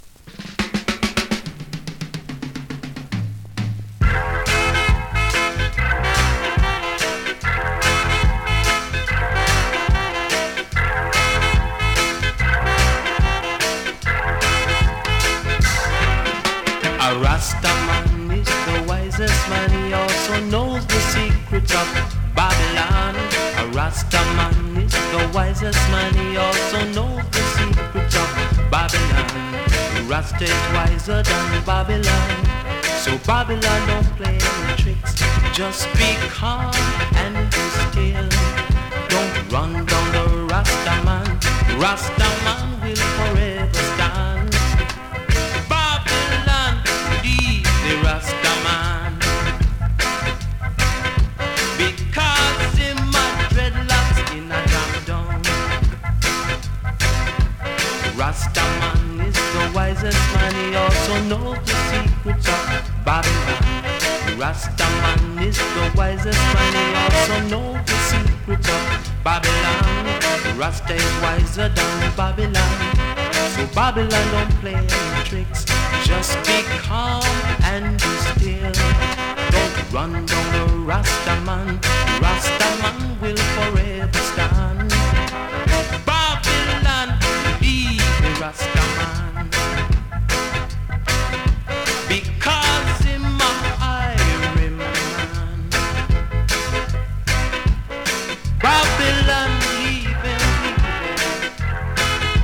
SKA〜REGGAE
スリキズ、ノイズかなり少なめの